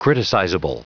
Prononciation du mot criticizable en anglais (fichier audio)
Prononciation du mot : criticizable